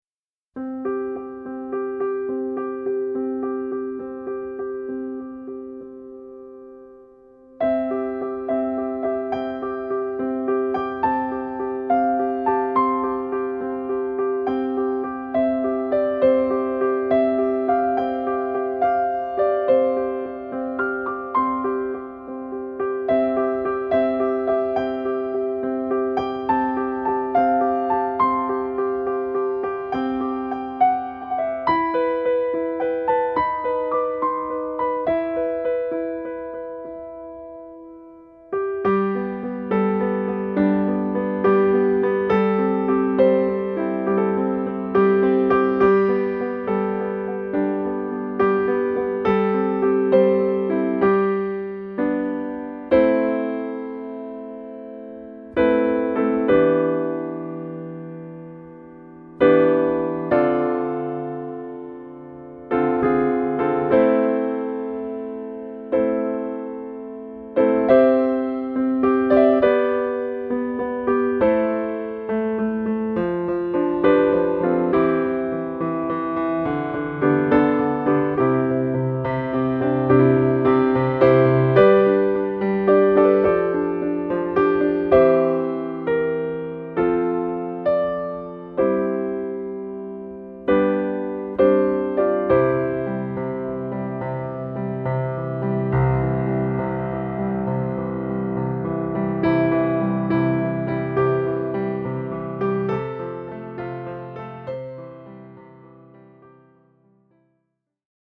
Level : Intermediate | Key : C | Individual PDF : $3.99